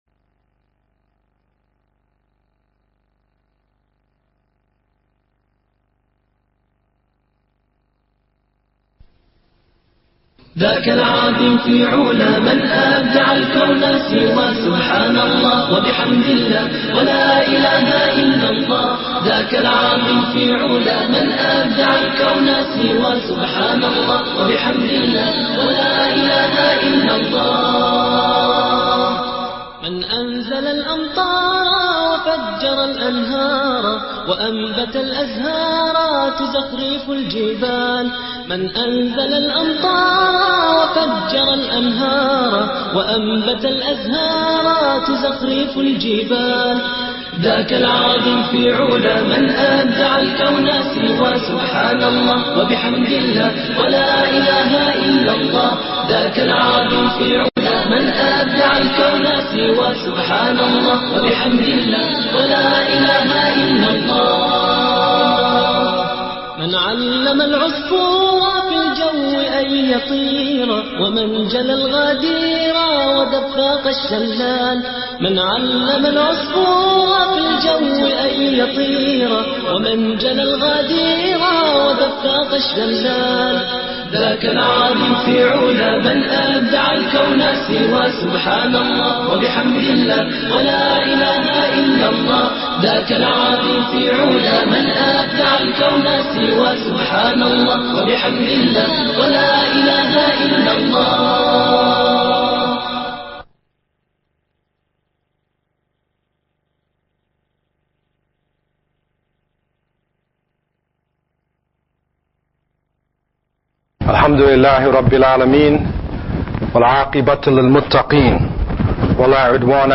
3180 views High Quality: Download (108.53 MB) Medium Quality: Download (67.17 MB) MP3 Audio (00:50:43): Download (23.54 MB) Transcript: Download (0.16 MB) The Signs of the Last Day An enlightening lecture about the path all of us will walk, from the day we enter into this life until we meet with Allah (Our Creator). This video lecture focuses on the Signs pointing towards the closeness of the Last Day.